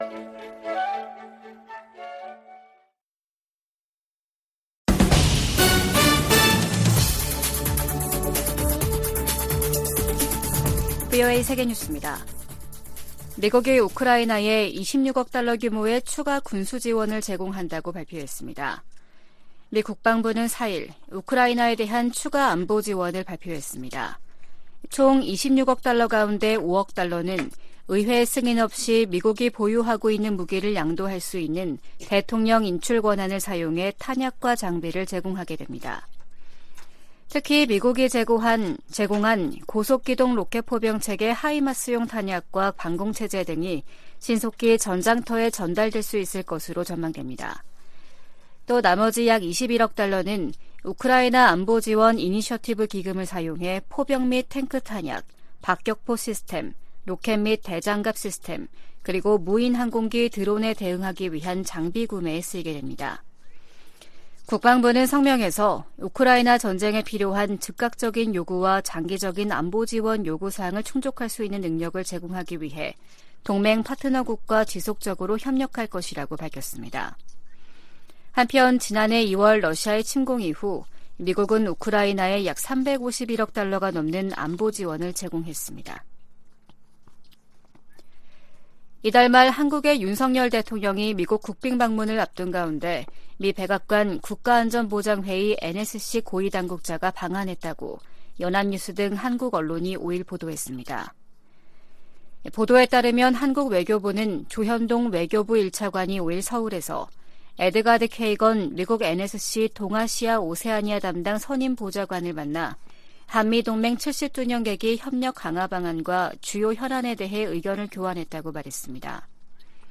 VOA 한국어 아침 뉴스 프로그램 '워싱턴 뉴스 광장', 2023년 4월 6일 방송입니다. 유엔 인권이사회가 북한의 조직적 인권 침해를 규탄하고 개선을 촉구하는 내용의 북한인권결의안을 채택했습니다. 한국 국방부는 5일 미 공군 B-52H 전략폭격기가 한반도 상공에서 한국 공군 F-35A 전투기 등과 연합공중훈련을 실시했다고 밝혔습니다.